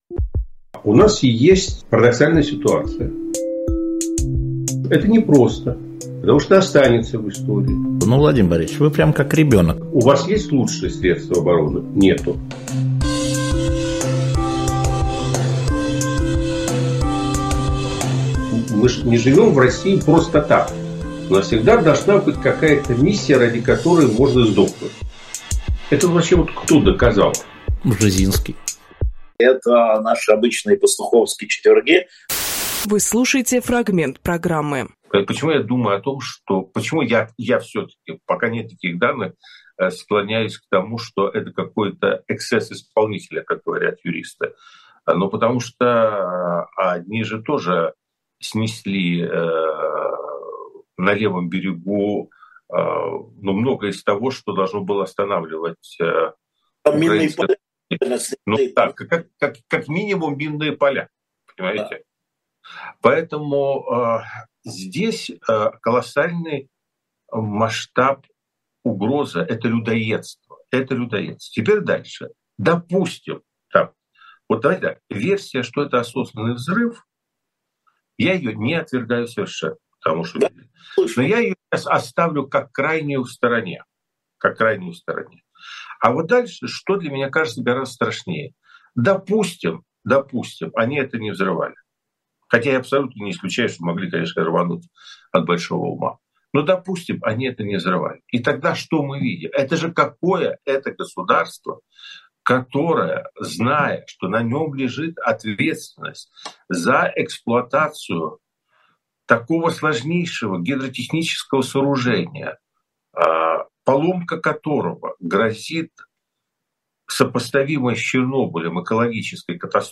Владимир Пастуховполитолог
Алексей Венедиктовжурналист
Фрагмент эфира от 08.06